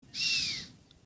Здесь вы можете слушать и скачивать их естественные голоса: от блеяния молодых особей до предупредительных сигналов взрослых.
Вариант 2 (записано в естественной среде)